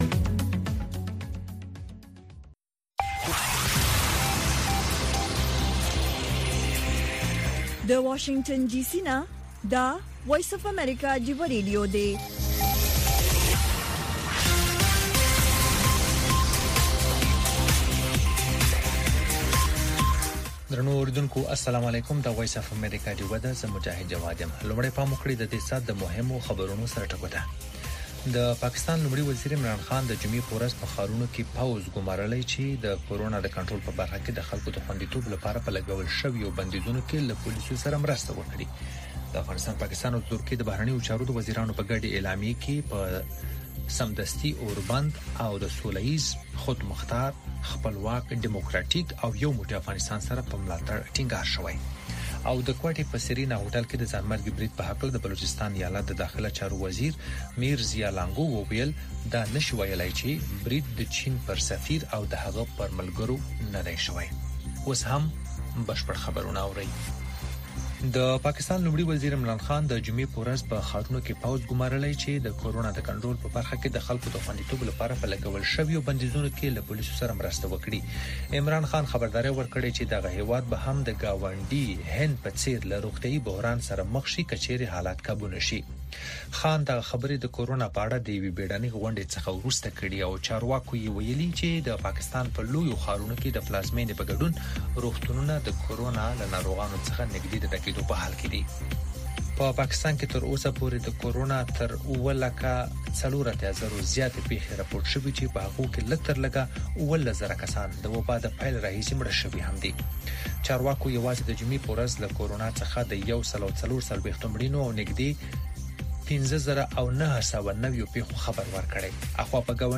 خبرونه
د وی او اې ډيوه راډيو سهرنې خبرونه چالان کړئ اؤ د ورځې دمهمو تازه خبرونو سرليکونه واورئ.